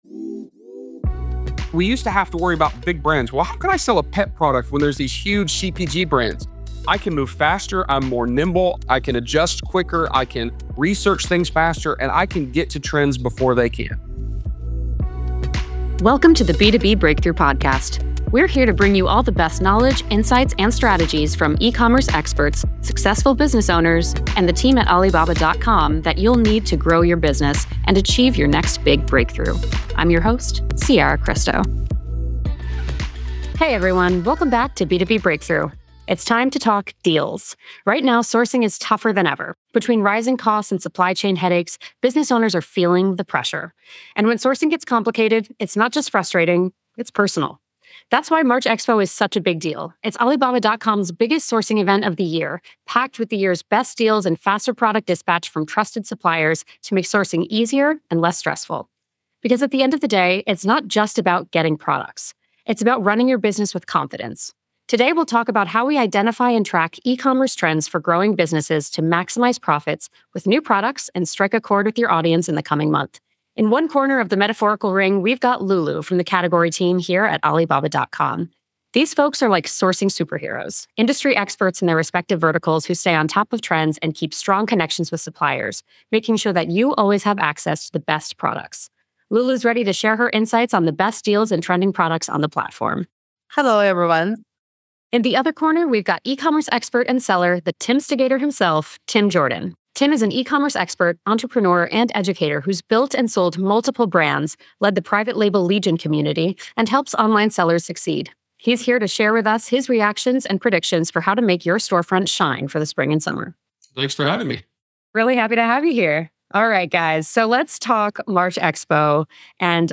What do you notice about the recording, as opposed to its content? recorded on the sidelines of Alibaba’s March Expo